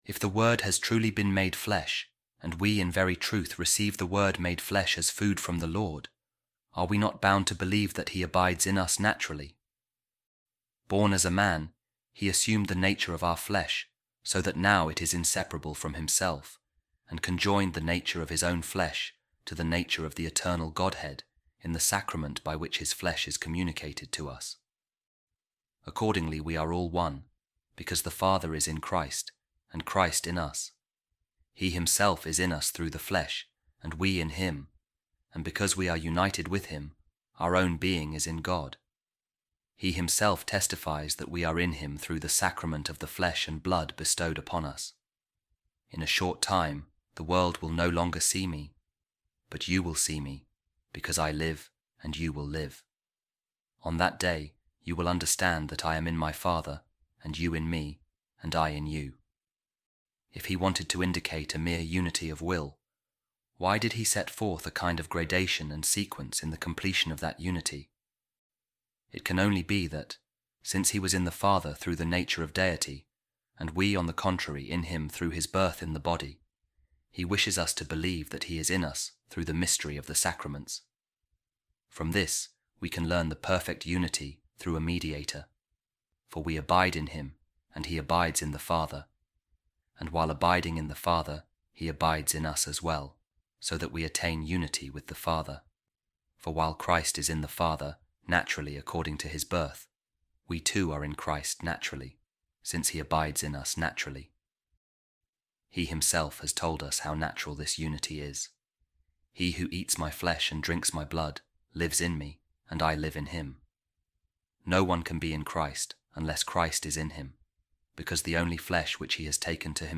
Office Of Readings | Eastertide Week 4, Wednesday | A Reading From The Treatise Of Saint Hilary Of Poitiers On The Trinity